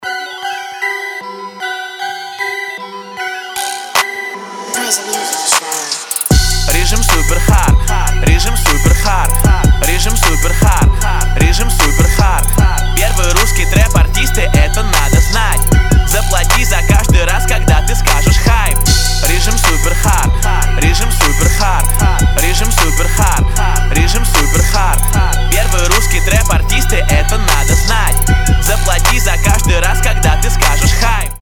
• Качество: 320, Stereo
русский рэп
Trap
качающие